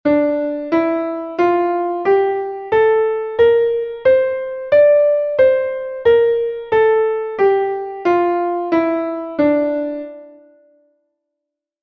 Re+Menor (audio/mpeg)